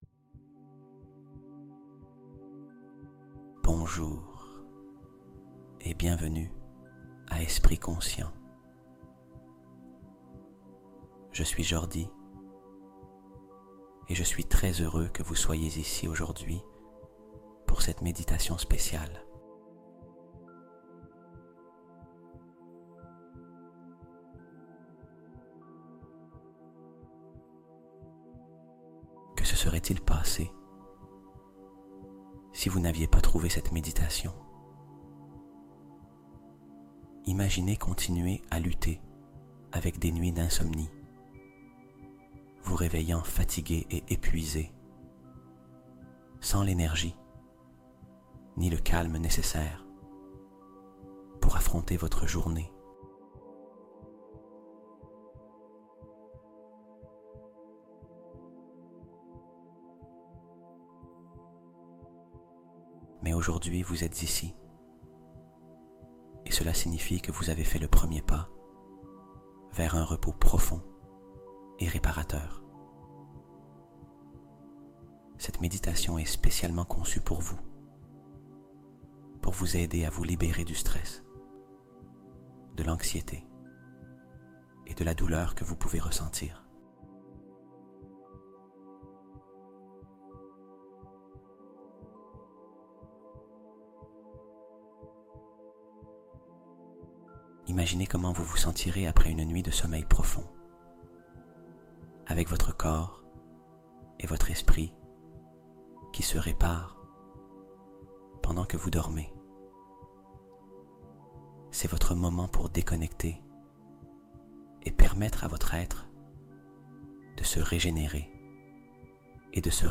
TON CORPS ENTIER VA VIBRER DIFFÉREMMENT | 777 Hz Réveille Des Pouvoirs Que Tu Ne Soupçonnais Pas